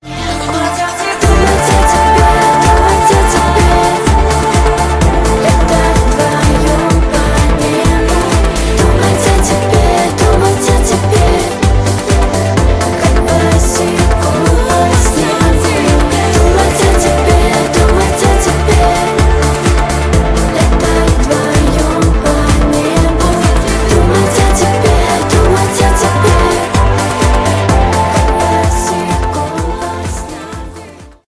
бесплатный рингтон в виде самого яркого фрагмента из песни
Ремикс
клубные